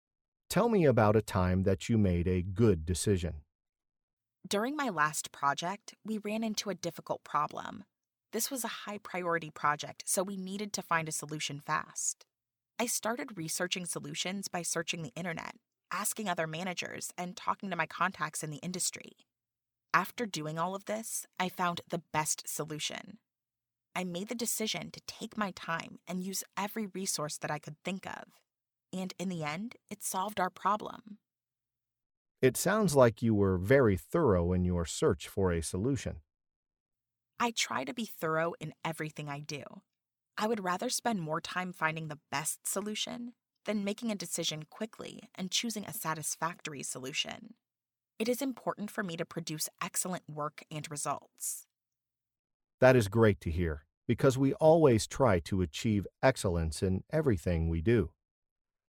Speak professional and fluent English. Learn different ways to answer the interview question 'Tell me about a time that you made a good decision.', listen to an example conversation, and study example sentences.